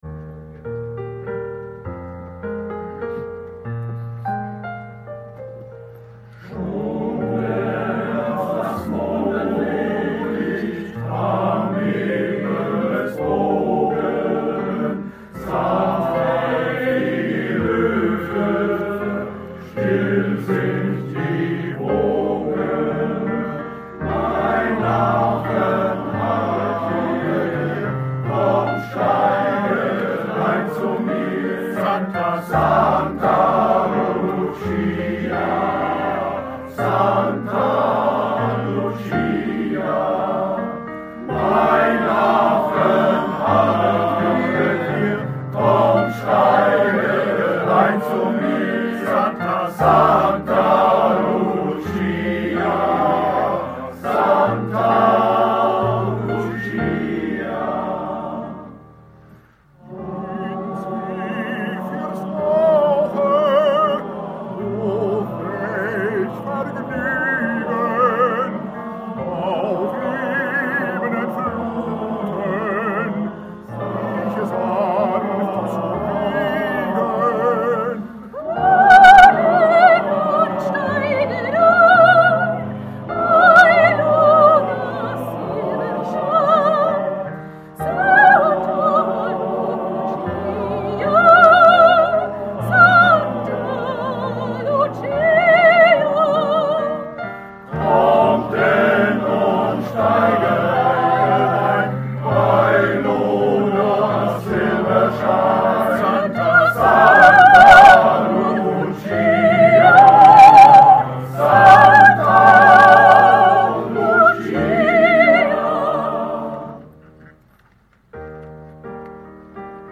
Wallufer Männerchöre